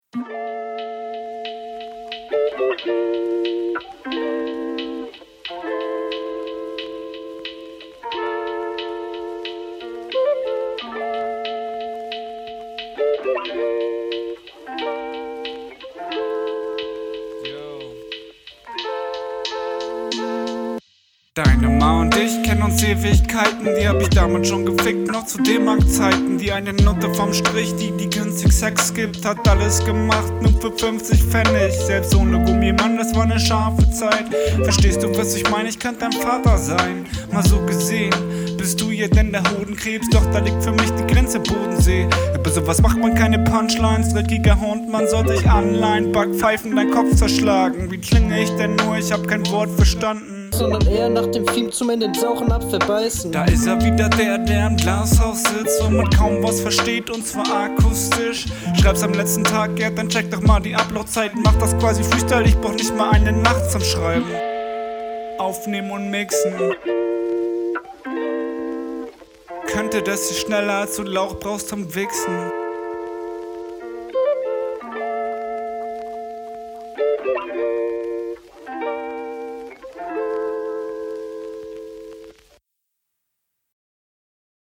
Mir gefällt dein Flow hier nicht.